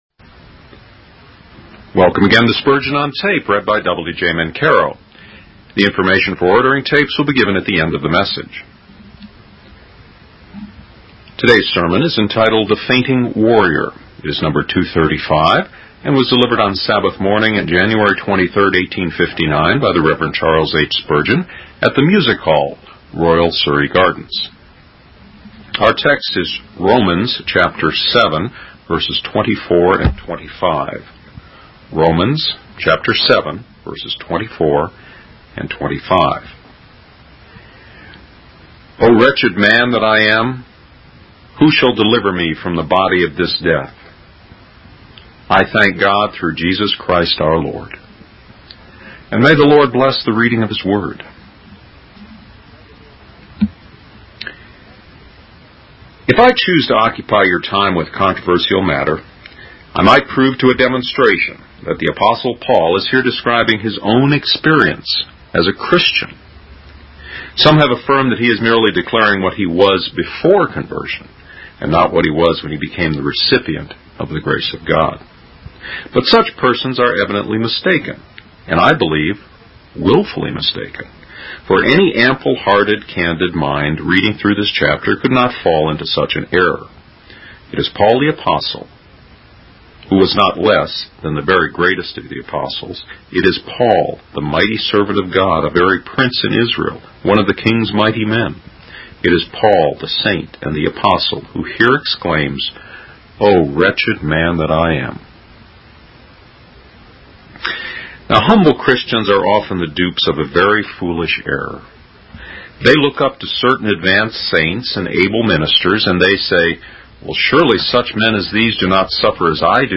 The Fainting Warrior -Spurgeon | SermonAudio Broadcaster is Live View the Live Stream Share this sermon Disabled by adblocker Copy URL Copied!